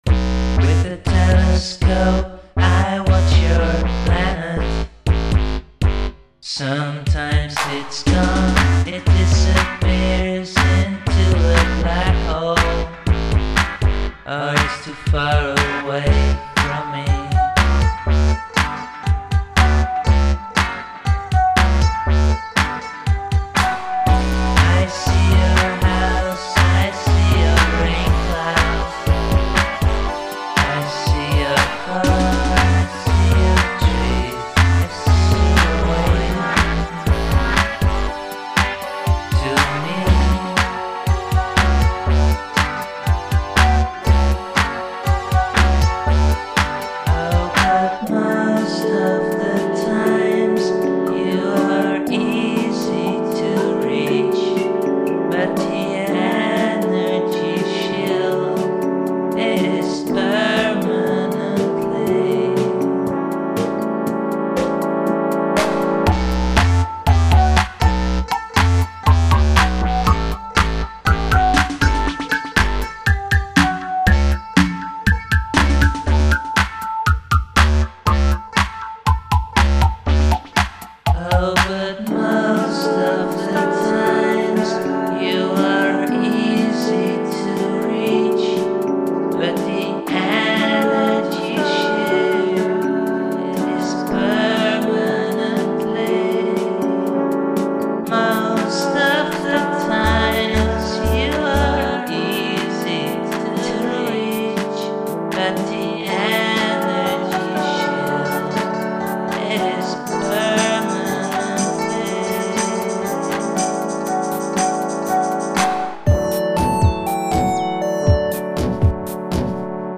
go tango in the second verse